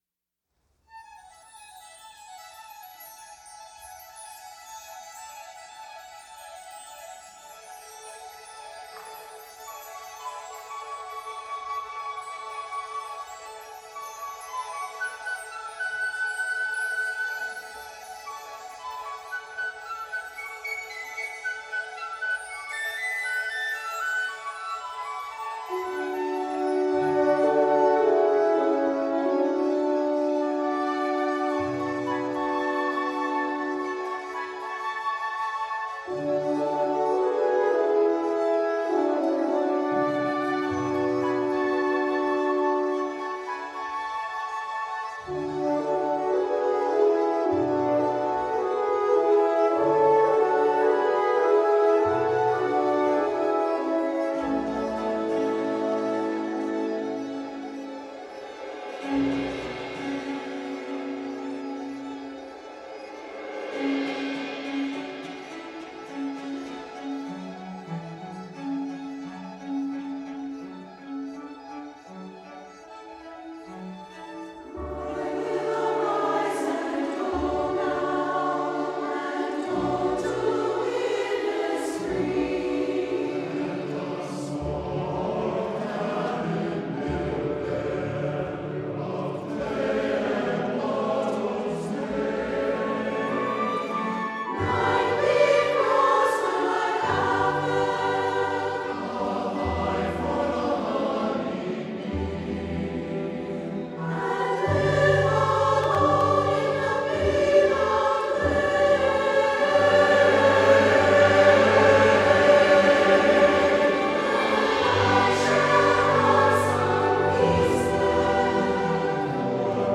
for SATB Chorus and Chamber Orchestra (2005)